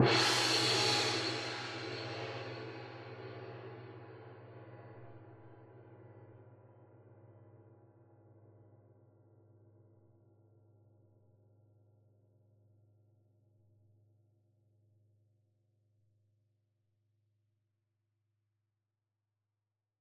Gong Hit High 2 - Cm.wav
Original creative-commons licensed sounds for DJ's and music producers, recorded with high quality studio microphones.
gong_hit_high_2_-_cm_ub7.ogg